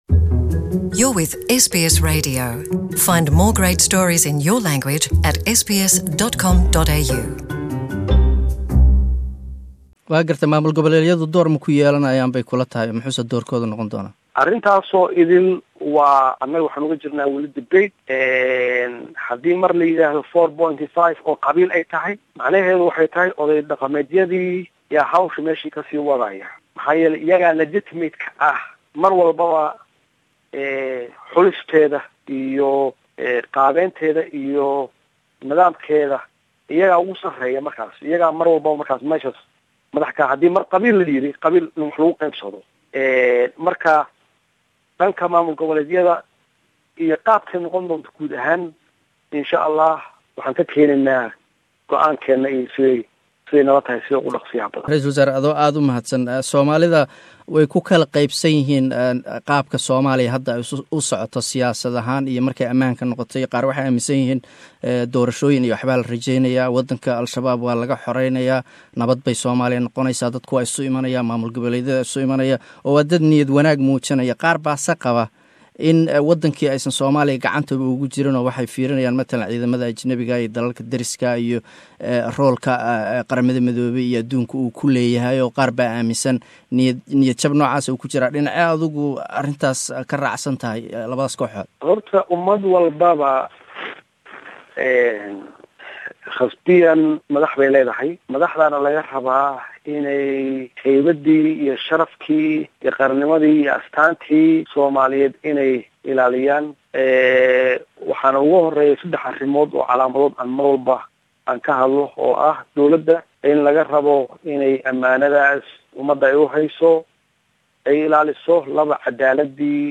SBS Somali archive: 2015 interview with then presidential candidate Mohamed Abdullahi Farmajo